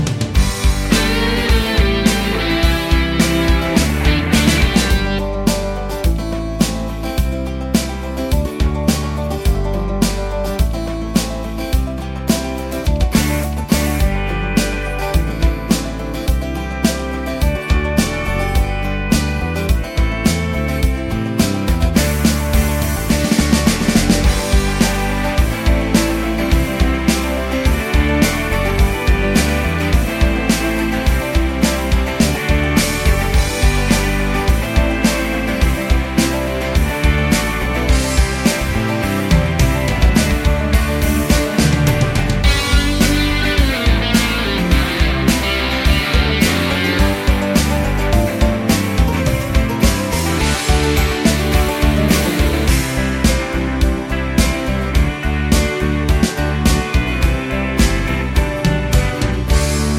no Backing Vocals Pop (2010s) 2:53 Buy £1.50